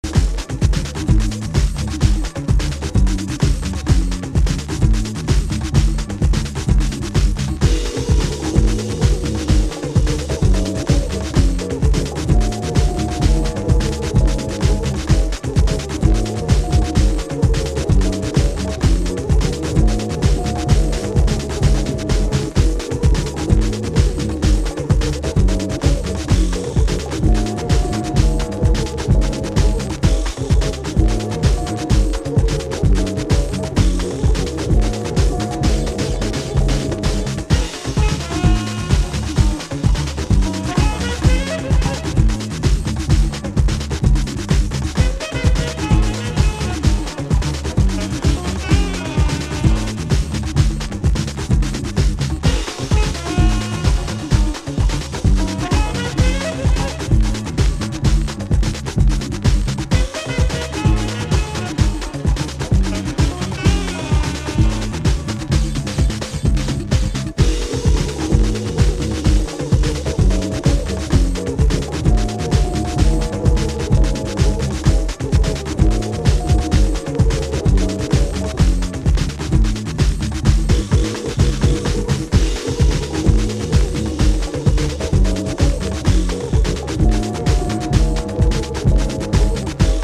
DEEP HOUSE / EARLY HOUSE
両面ともトライバルなパーカッションでグルーヴ満載の1枚！